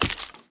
minecraft / sounds / mob / bogged / step4.ogg